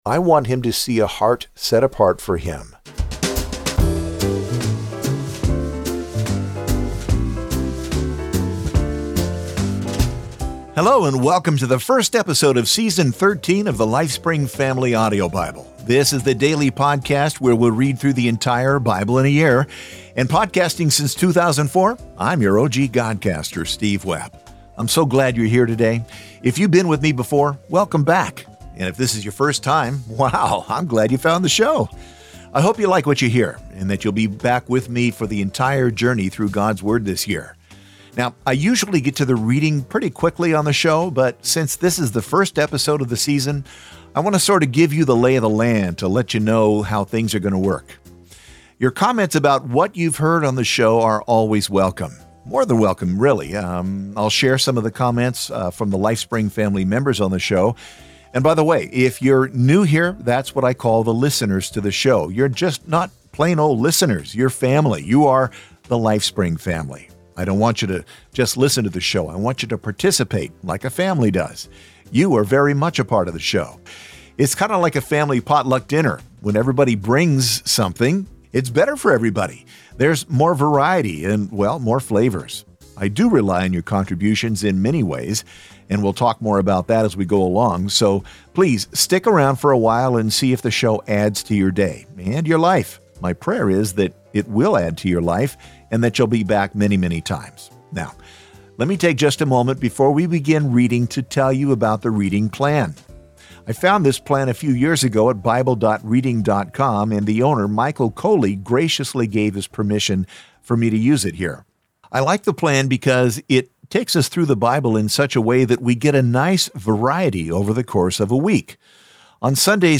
Each episode features a reading, followed by a short commentary.